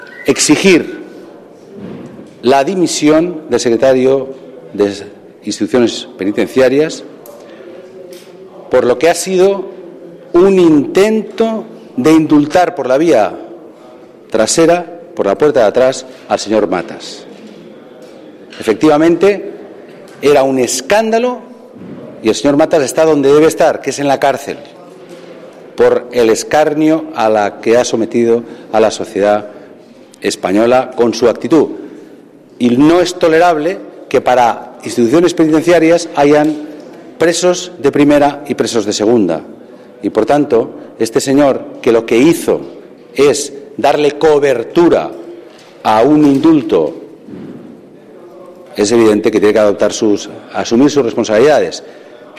Declaraciones de Ximo Puig en el Congreso pidiendo la dimisión del secretario general de Instituciones Penitenciarias después de que los tribunales anularan la concesión del tercer grado 11/12/2014